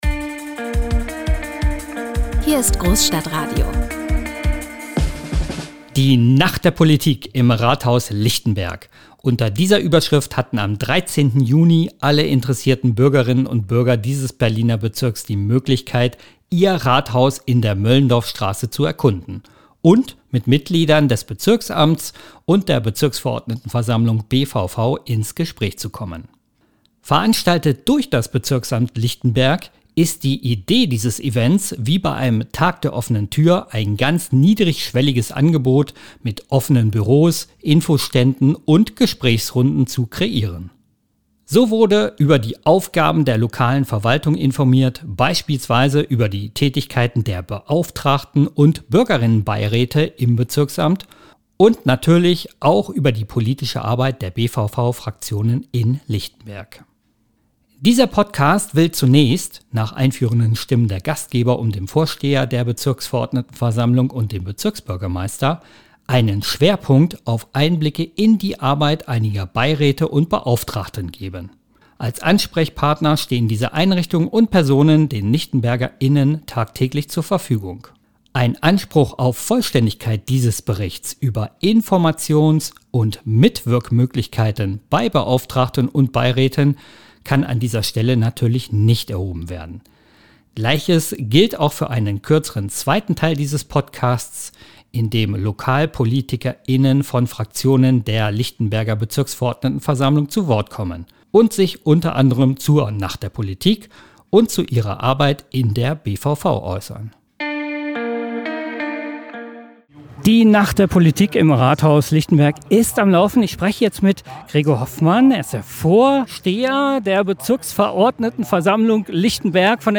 Dieser Podcast will zunächst – nach einführenden Stimmen der Gastgeber um den Vorsteher der Bezirksverordnetenversammlung und den Bezirksbürgermeister – einen Schwerpunkt auf Einblicke in die Arbeit einiger Beiräte und Beauftragten geben.
Gleiches gilt auch für einen kürzeren zweiten Teil dieses Podcasts in dem Lokalpolitiker:innen von Fraktionen der Lichtenberger Bezirksverordnetenversammlung zu Wort kommen – und sich u.a. zur Nacht der Politik und zu ihrer Arbeit in der BVV äußern. In diesem Podcast soll ein Eindruck davon vermittelt werden, wie Lokalpoliter:innen in der Bezirksverordnetenversammlung Lichtenberg arbeiten und entscheiden.